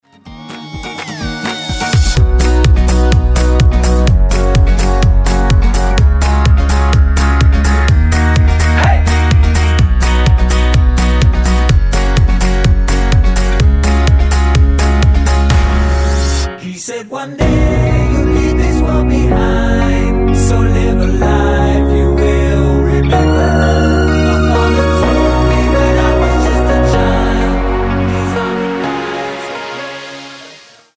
--> MP3 Demo abspielen...
Tonart:Ebm mit Chor